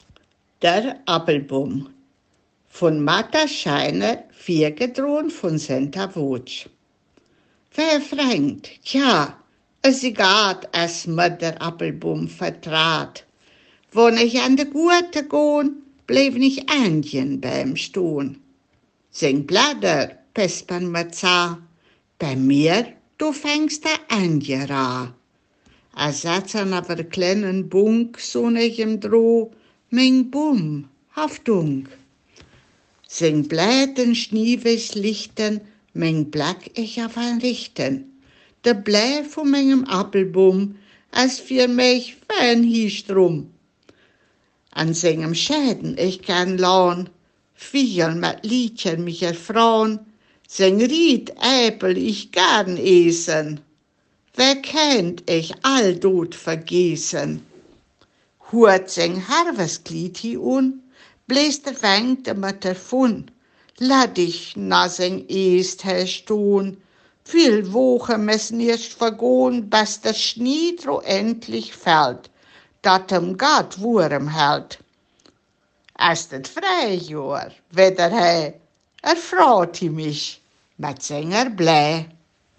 Ortsmundart: Hermannstadt